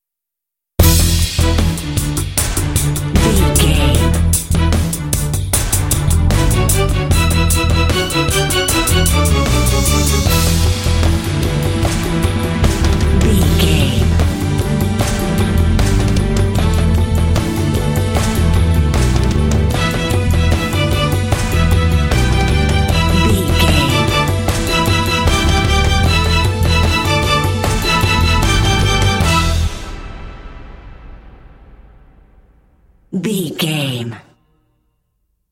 Epic / Action
Fast paced
Aeolian/Minor
dramatic
foreboding
suspense
strings
drums
bass guitar
orchestral
film score
symphonic rock